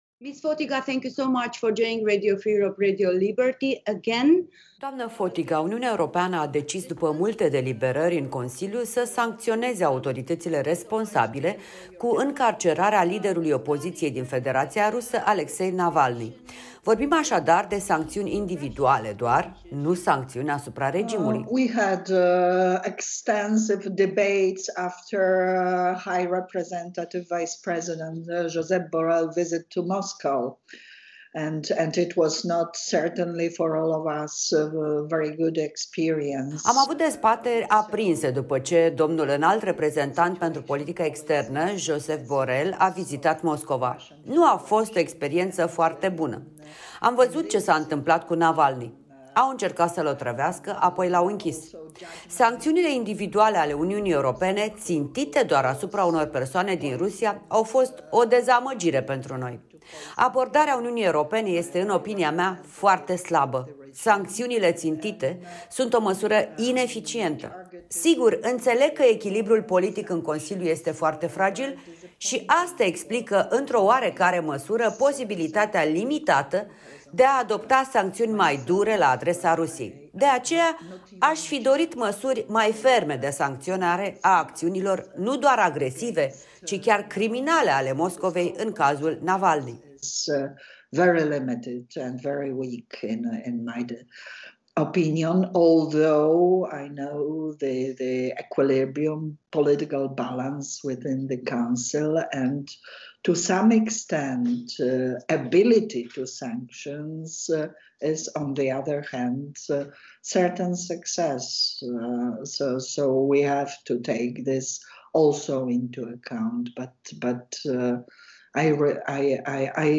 Interviu în exclusivitate